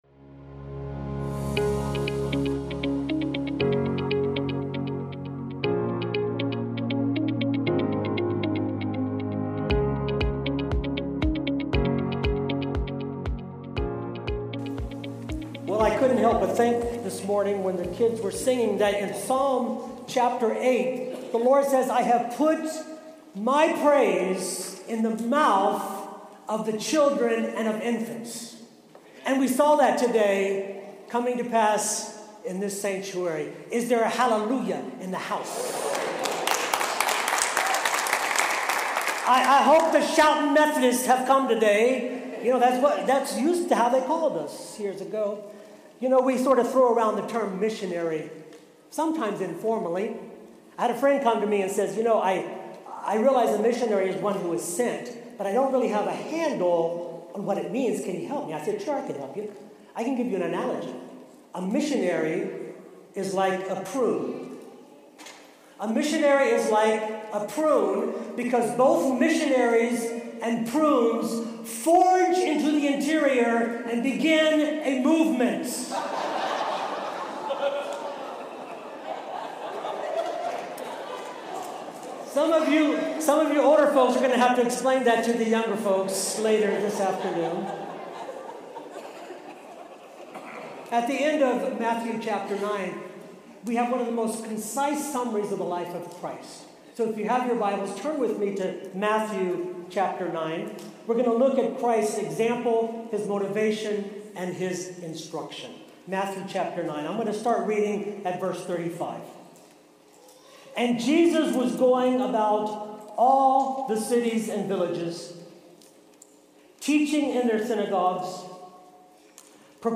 (June 18, 2023-Combined Worship-Stand Alone) “The Ministry of Christ”